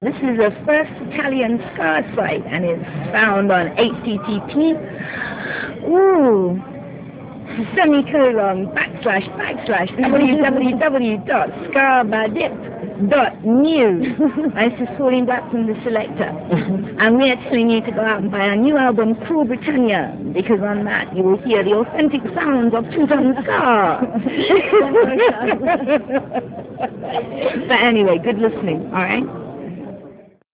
jingle_pauline-black.ra